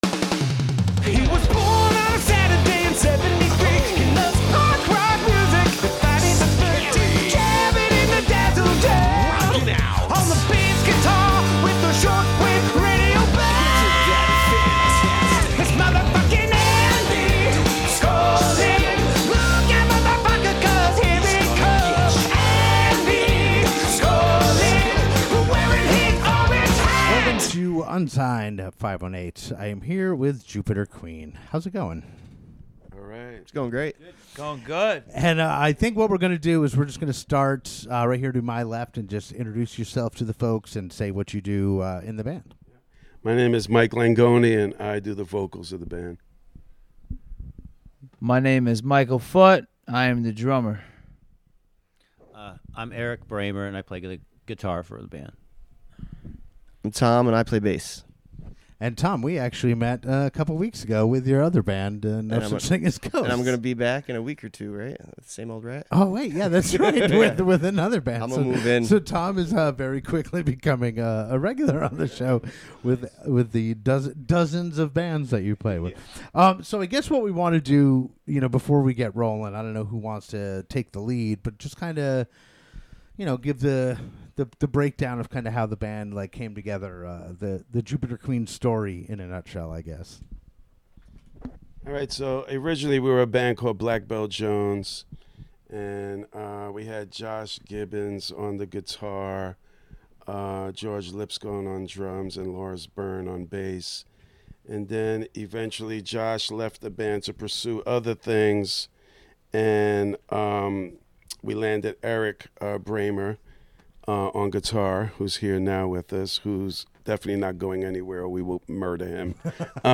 Jupiter Queen has a brilliant garage-rock energy that is propelled by tight songwriting and solid musicianship. On this episode Jupiter Queen, in a first for Unsigned518, set up and played just like they were at a club gig. While I've had some acoustic performances in the Dazzle Den, having a full band at full volume, was really cool!